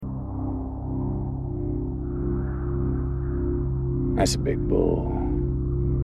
bull-matthew-mcconaughey-and-the-mkc-official-commercial-lincoln-1.mp3